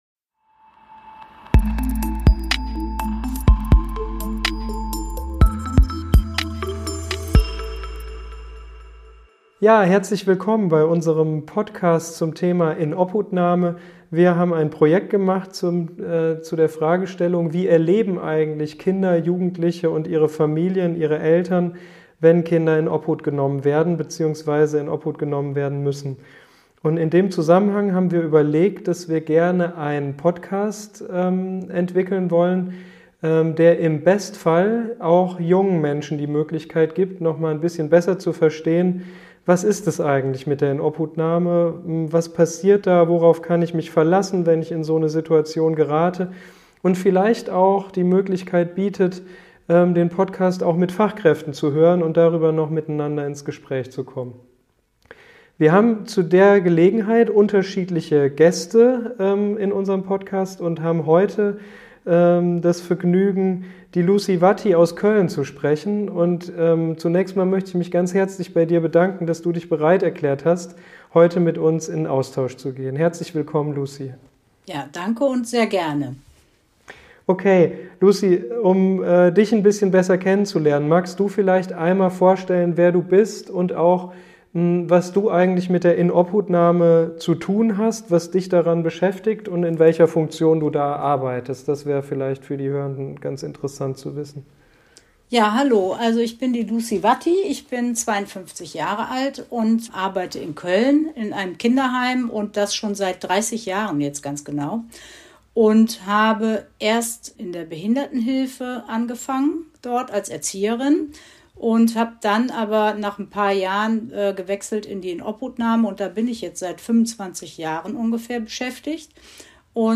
Sie berichtet über den Ablauf, was passiert, wenn Kinder oder Jugendliche im Rahmen einer Inobhutnahme in eine Gruppe einziehen. Gemeinsam legen die beiden im Gespräch ein Augenmerk auf die Kinderrechte.